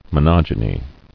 [mo·nog·e·ny]